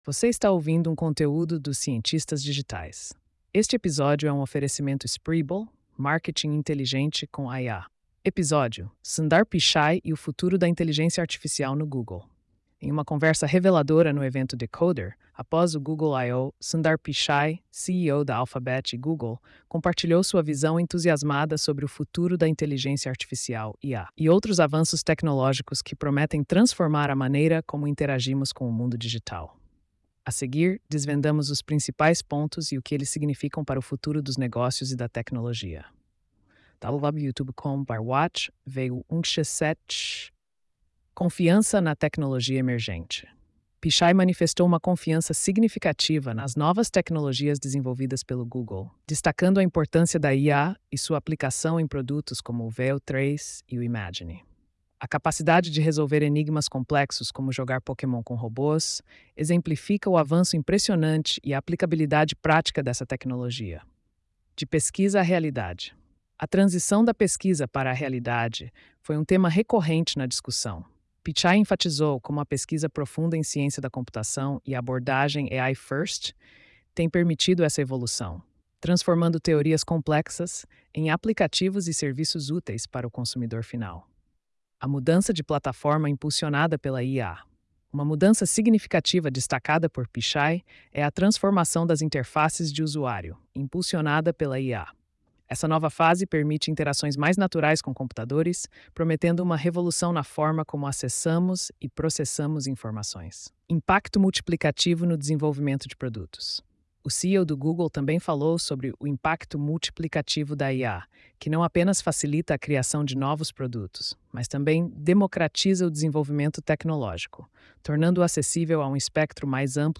post-3385-tts.mp3